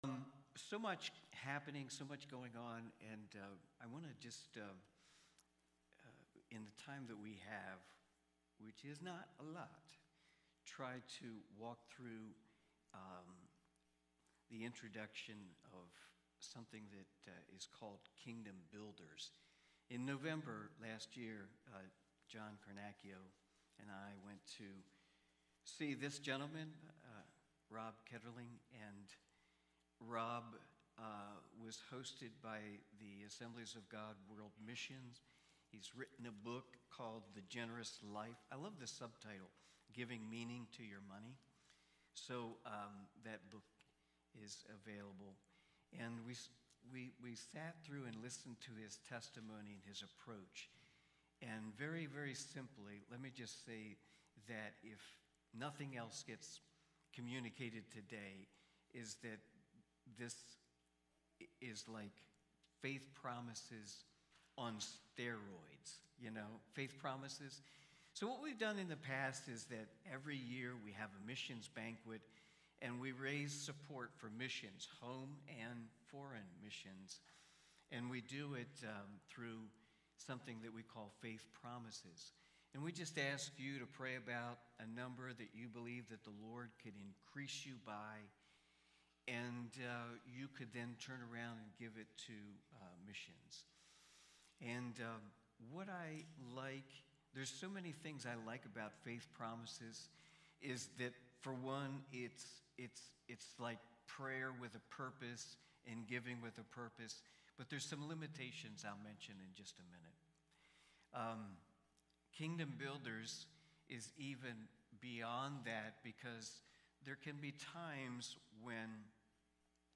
Missions Watch Listen Save Cornerstone Fellowship Sunday morning service, livestreamed from Wormleysburg, PA.